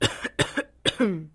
Подросток кашляет